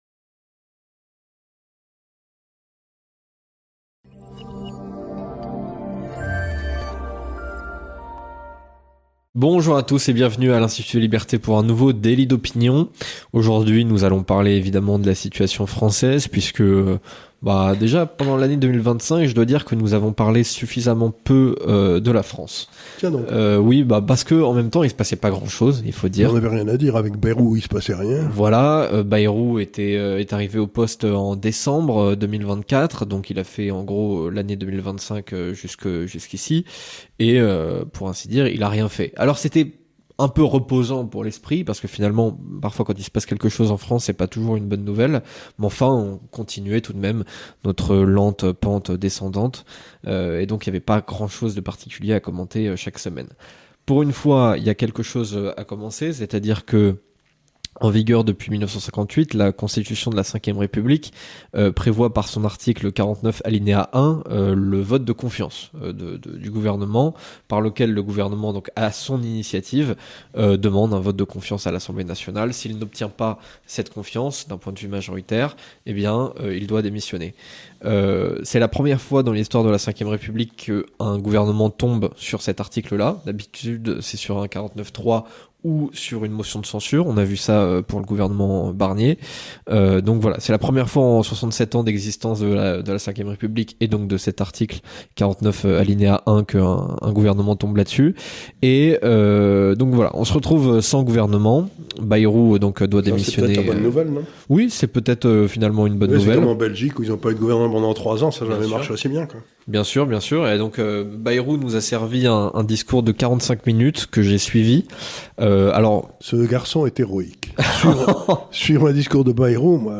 Dans l'entretien de cette semaine